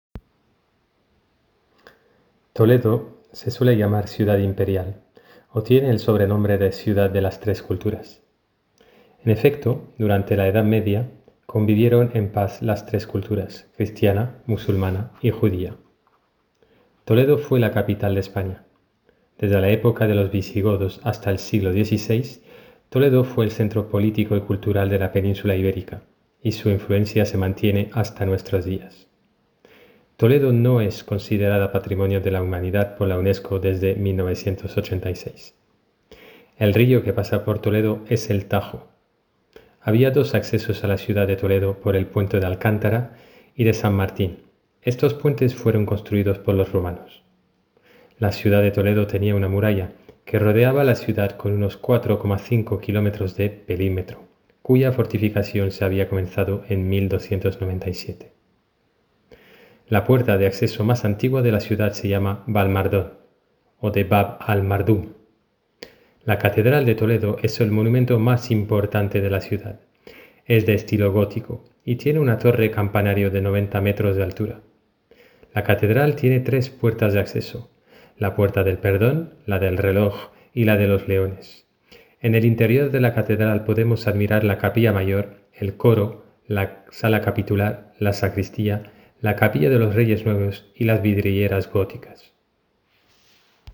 • LECTURA DEL PROFESOR: